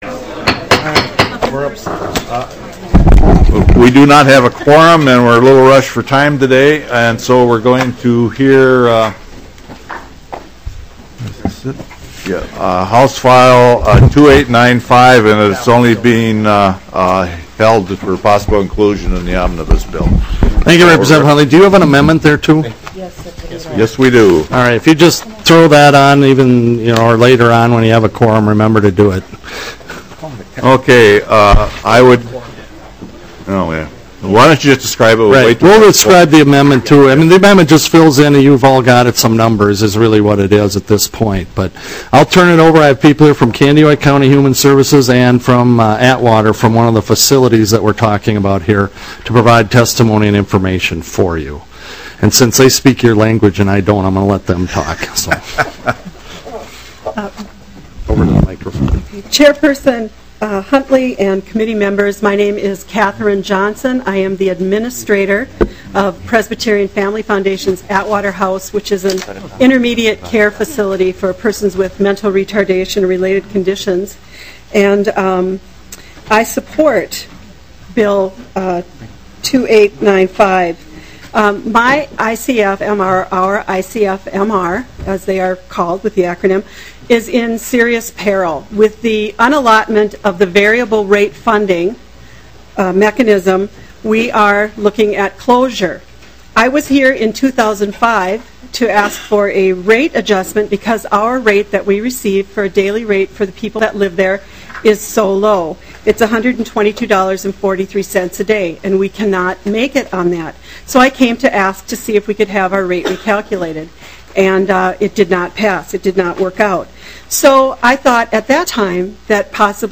03:43 - Gavel, and consideration of HF2895 (Juhnke) ICF/MR variable payment rates modified. 12:55 - HF802 (Murphy) General Assistance Medical Care reform. 25:02 - Public testimony on HF802.